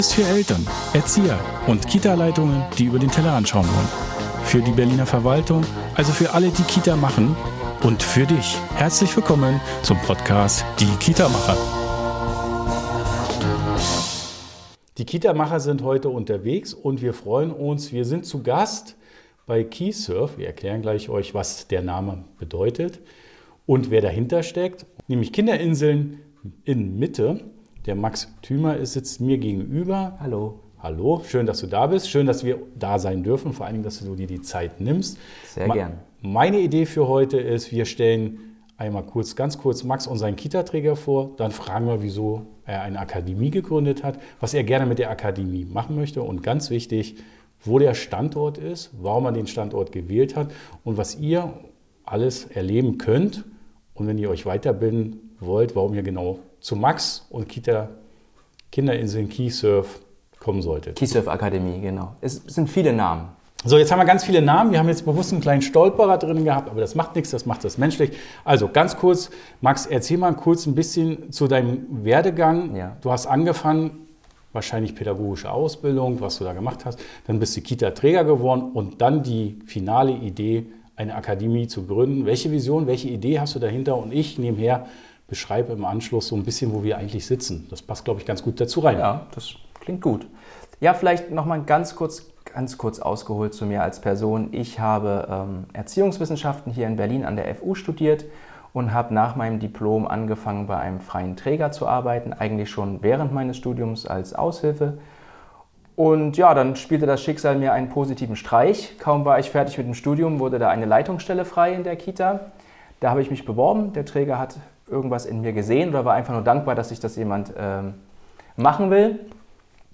Tauchen Sie mit uns ein in ein spannendes Gespräch über die Leitprinzipien von kiServ, über Kitas, Demokratie, Coding für Kinder und vieles mehr.